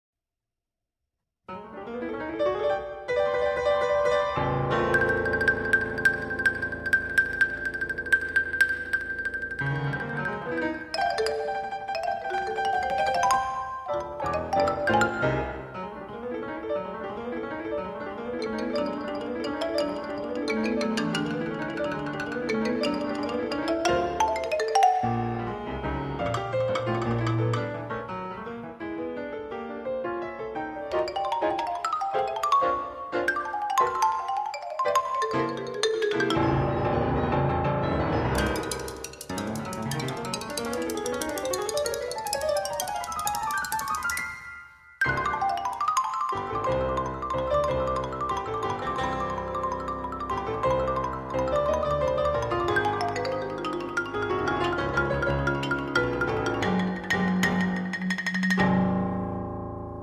alto saxophone
violin